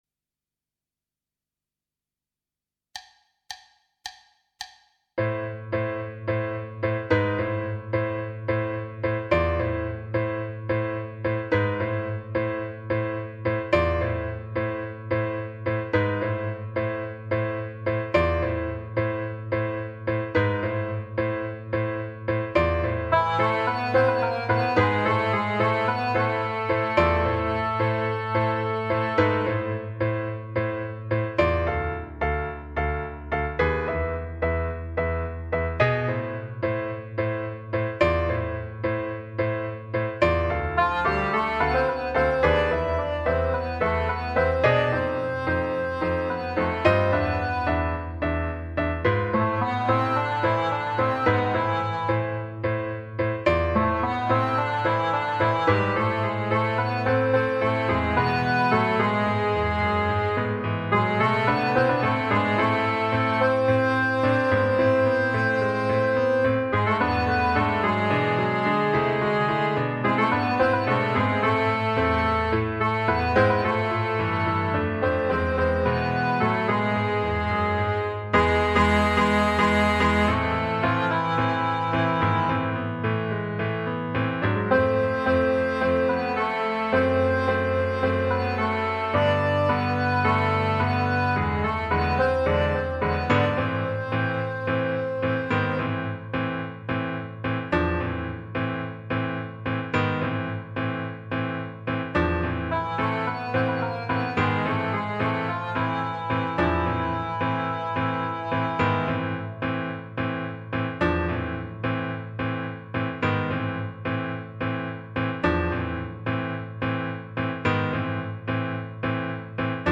minus Instrument 1